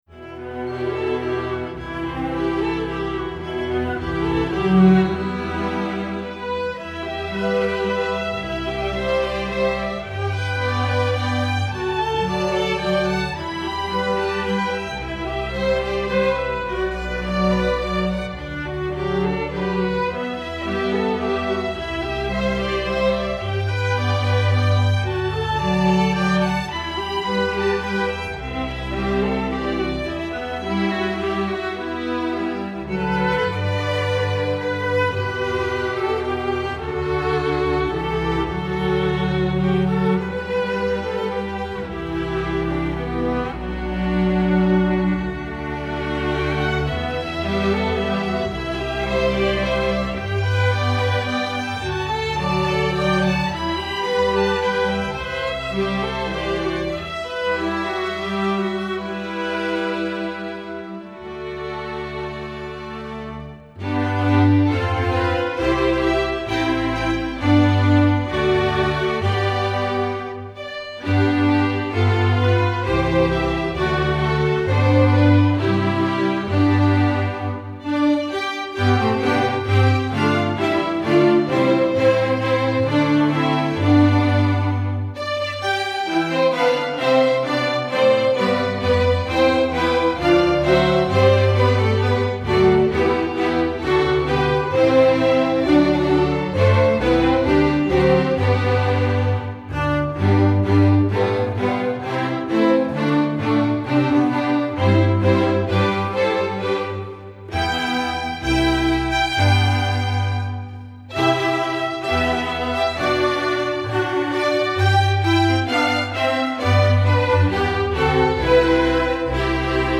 instructional, children